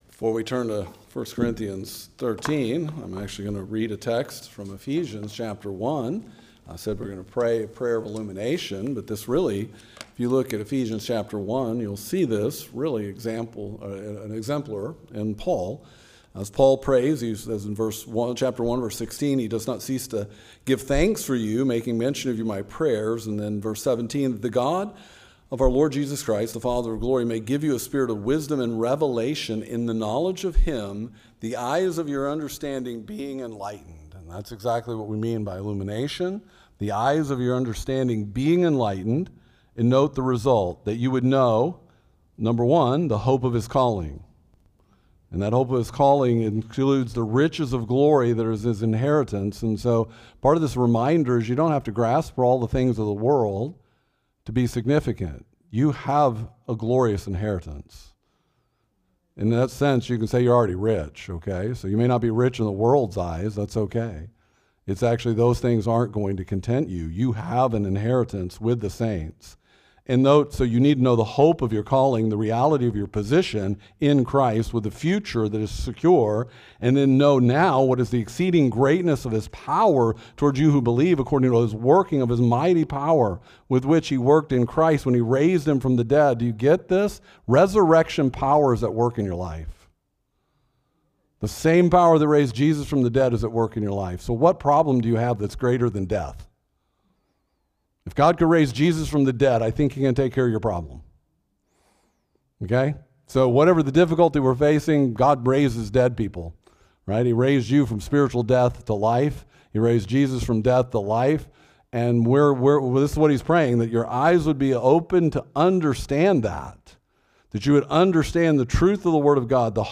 March-22-AM-Service.mp3